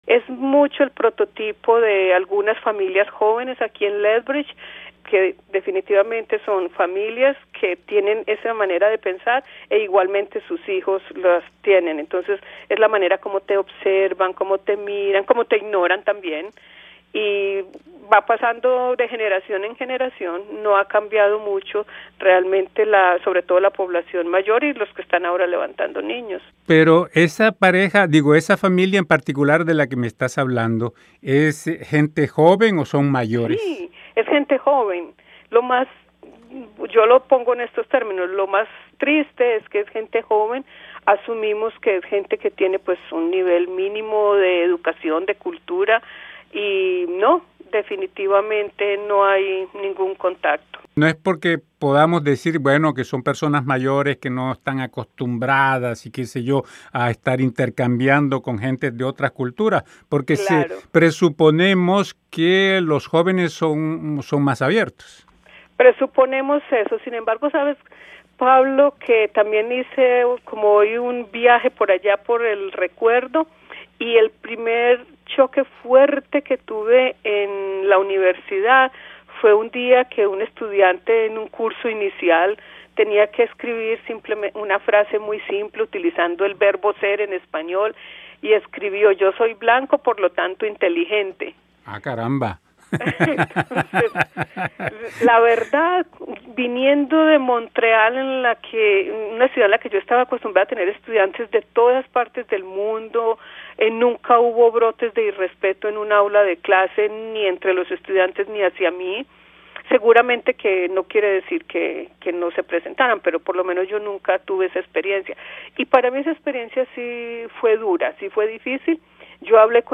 En términos generales, ella estaba satisfecha, pero no conforme, nos dice en entrevista.